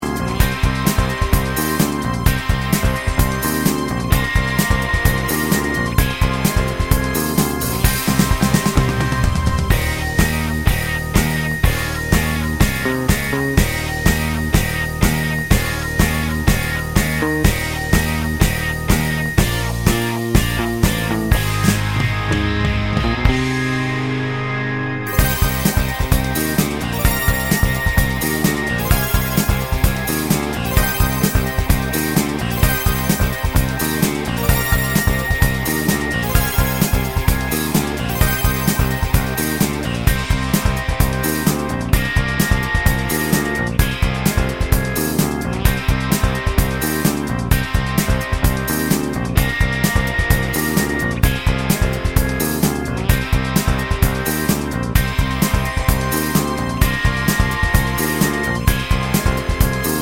no Backing Vocals Rock 3:42 Buy £1.50